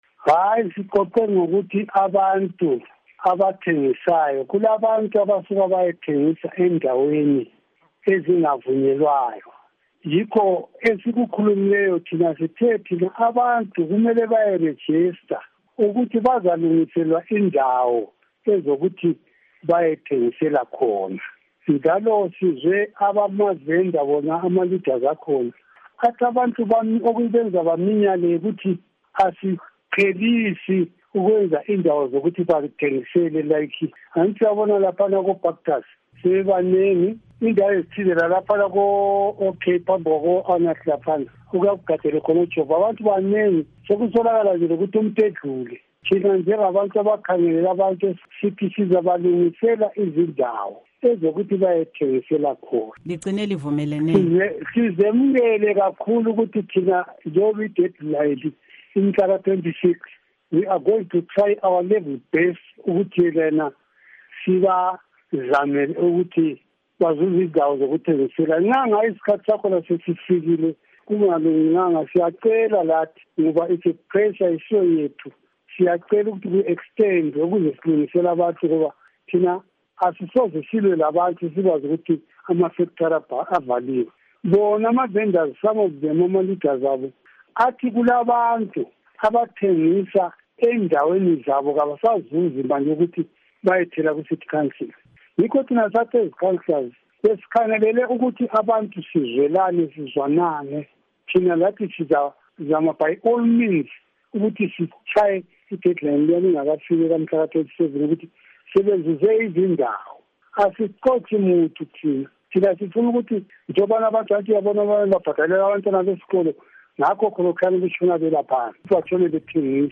Ingxoxo LoKhansila Lot Siziba